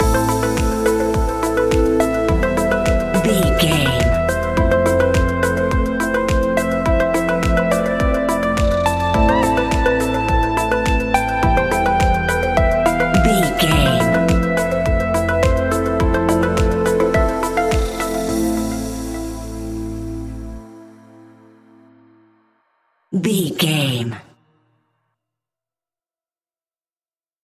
Evening Tropical House 15 Sec.
Aeolian/Minor
groovy
calm
smooth
dreamy
uplifting
piano
drum machine
synthesiser
house
synth leads
synth bass